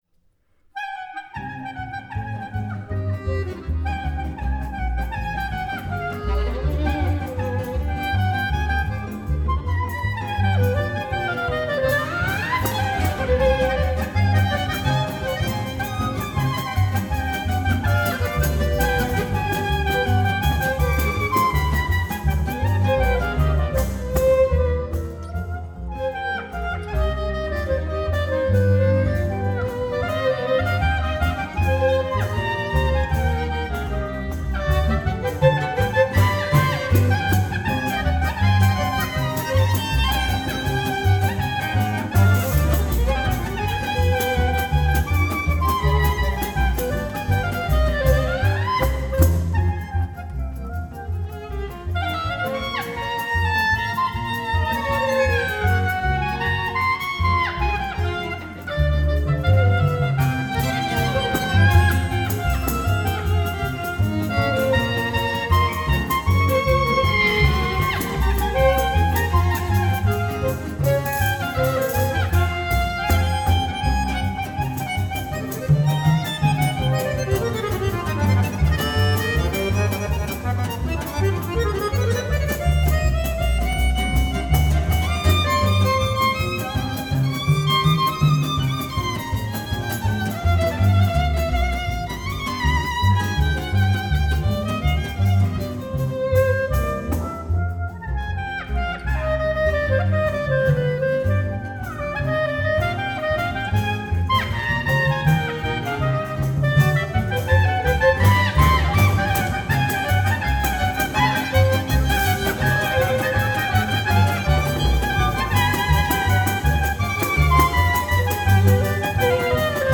кларнет
Genre: Folk, World,